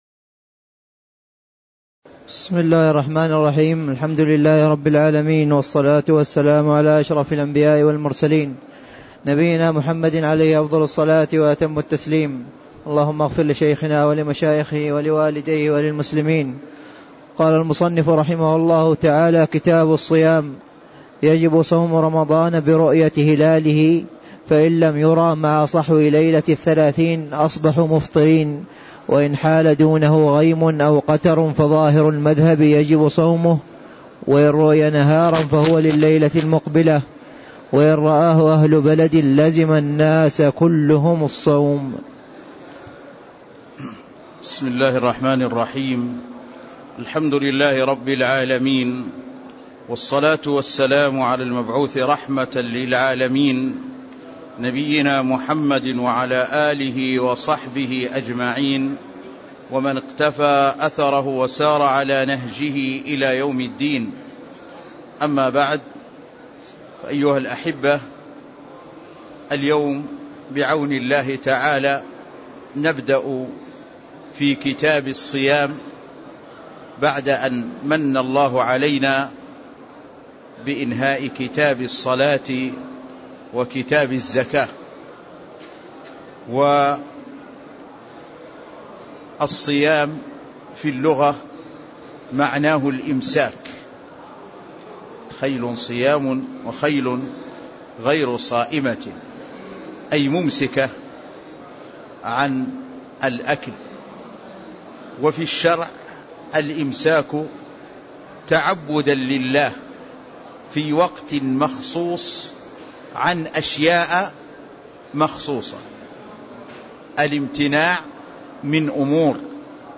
تاريخ النشر ٢٨ رمضان ١٤٣٦ هـ المكان: المسجد النبوي الشيخ